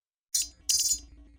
Звук полученного письма на электронный ящик, мелодии сообщений и уведомлений в mp3
9. Сообщение электронной почты получено